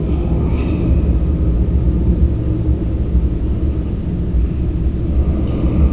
ambience6.wav